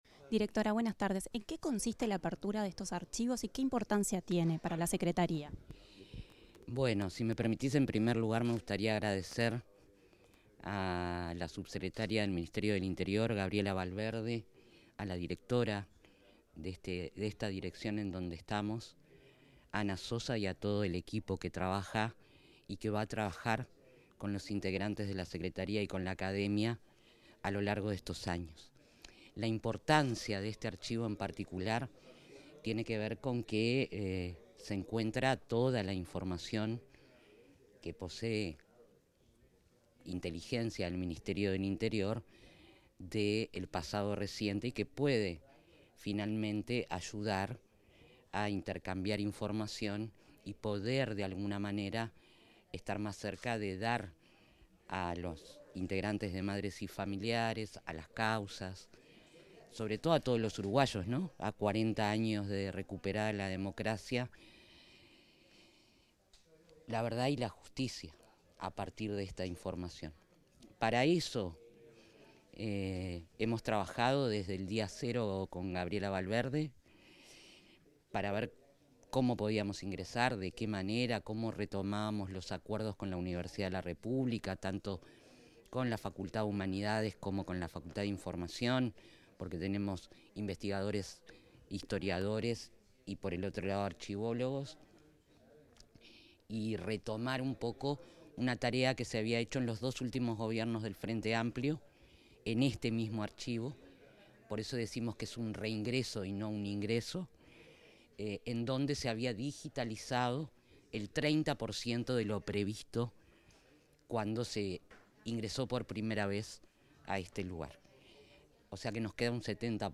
Declaraciones de la secretaria de Derechos Humanos para el Pasado Reciente, Alejandra Casablanca